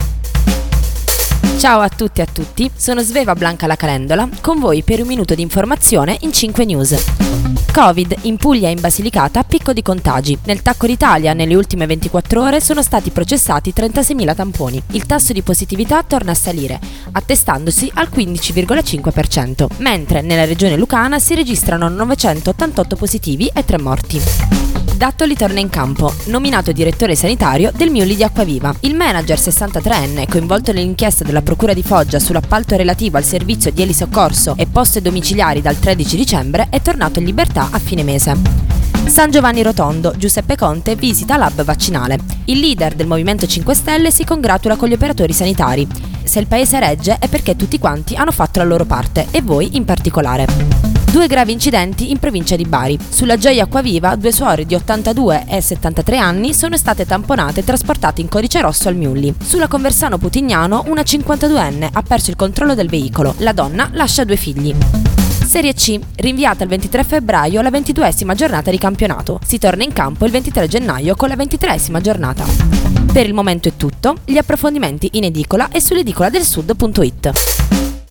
Giornale radio alle ore 7.